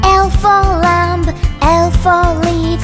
Phonics